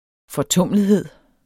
Udtale [ fʌˈtɔmˀləðheðˀ ]